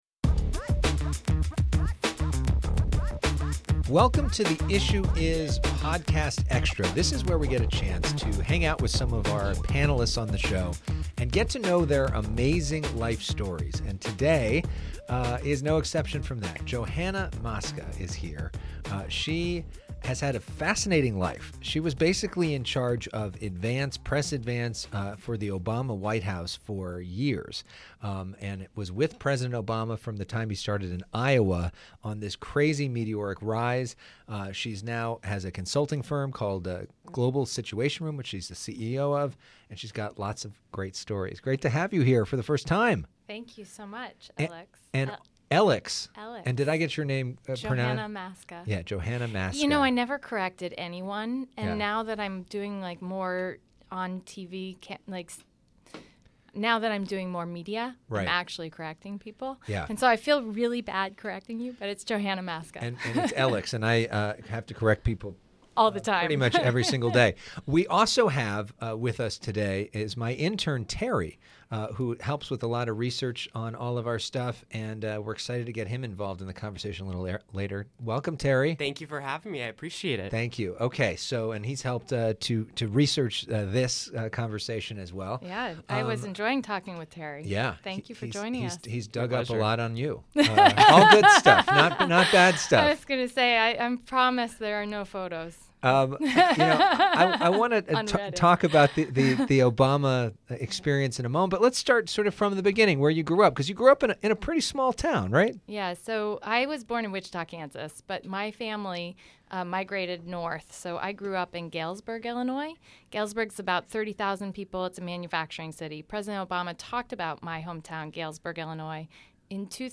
a candid and revealing conversation
broadcast from FOX 11 Studios in Los Angeles.